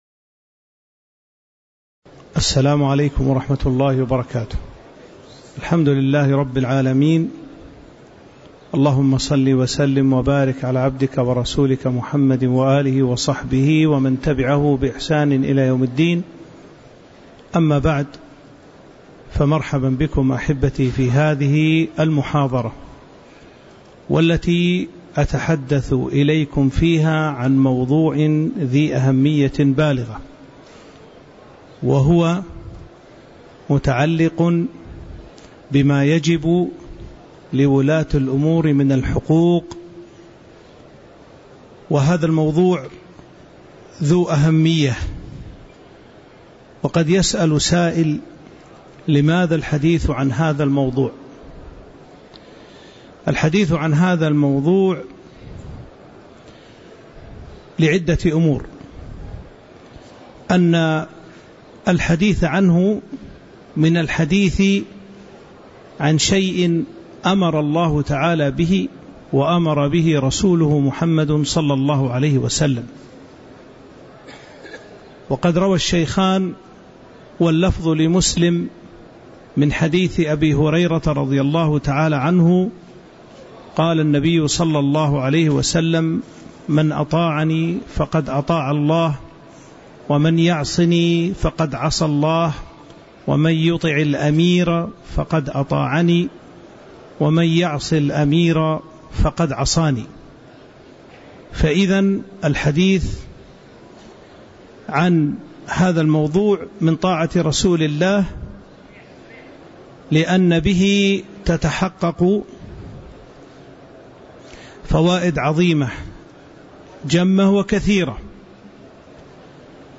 تاريخ النشر ٢١ ربيع الأول ١٤٤٦ هـ المكان: المسجد النبوي الشيخ